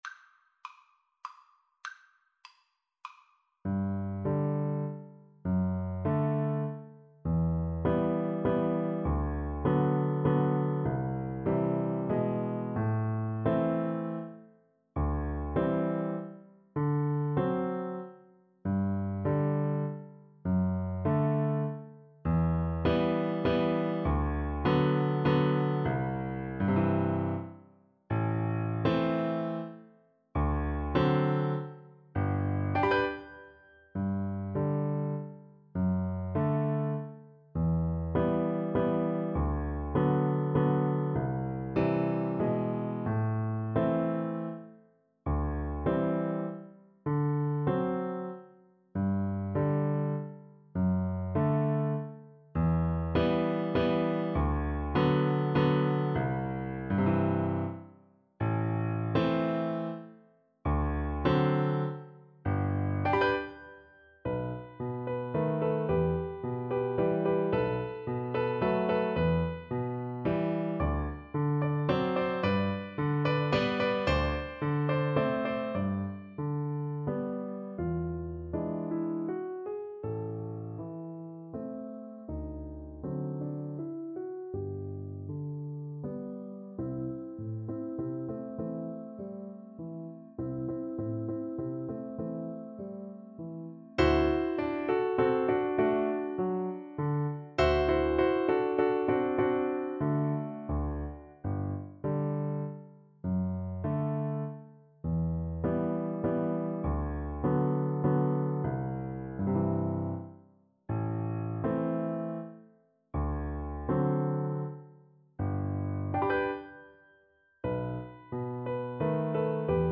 Play (or use space bar on your keyboard) Pause Music Playalong - Piano Accompaniment Playalong Band Accompaniment not yet available transpose reset tempo print settings full screen
3/4 (View more 3/4 Music)
G major (Sounding Pitch) (View more G major Music for Cello )
~ = 100 Tranquillamente
Classical (View more Classical Cello Music)